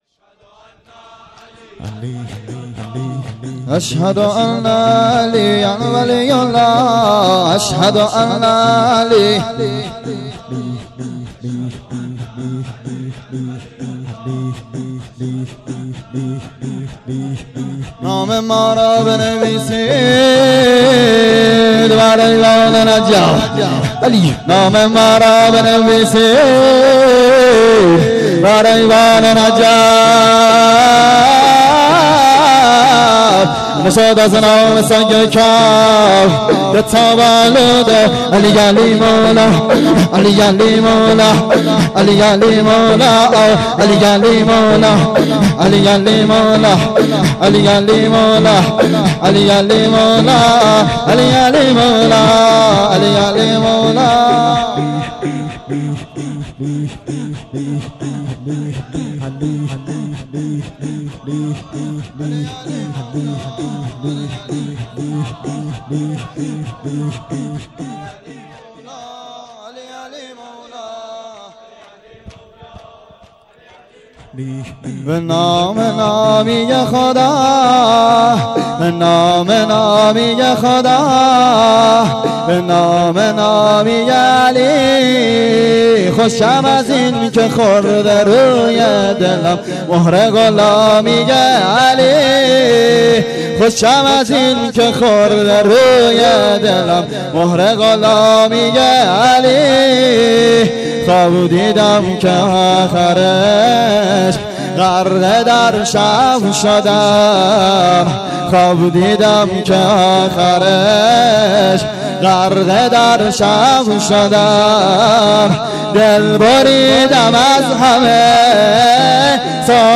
مراسم احیای شب قدر ۲۰ رمضان ۱۴۰۴